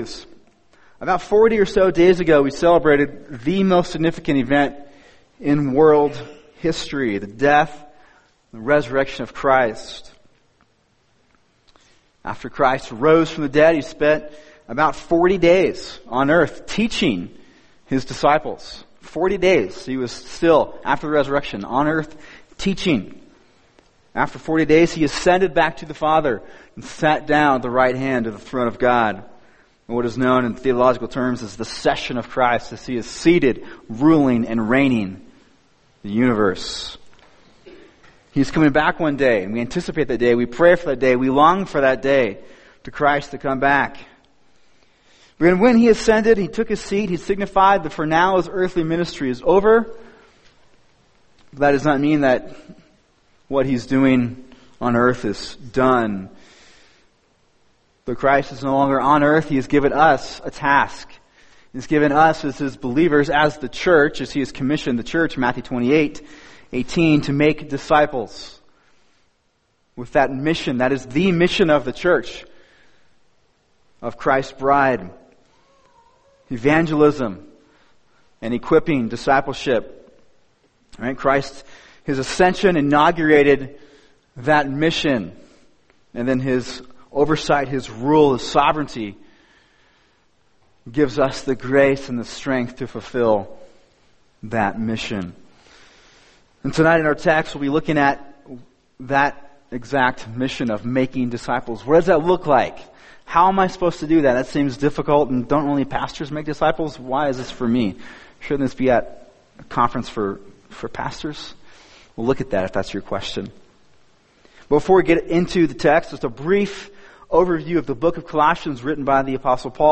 [sermon] Colossians 1:28-29 A Discipleship Culture | Cornerstone Church - Jackson Hole